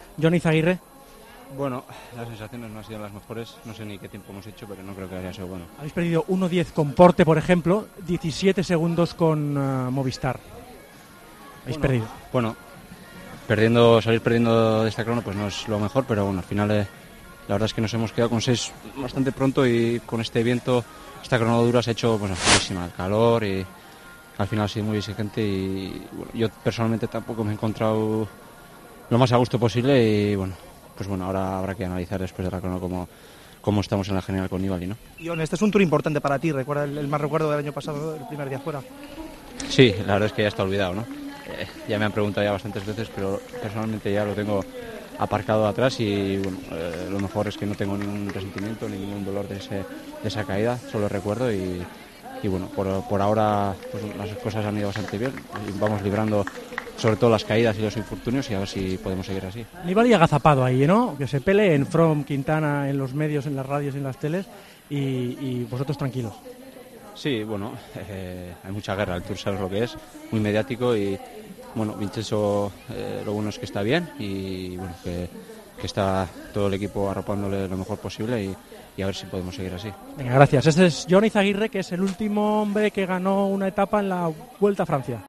El corredor del Bahrein valora la contrarreloj por equipos ante los medios: "Nos hemos quedado con seis demasiado pronto y la crono se ha hecho dura. Yo tampoco he estado cómodo y tenemos que analizar ahora como nos quedamos en la clasificación con Nibali. Vamos librando caídas. Nibali está bien y el equipo le está arropando".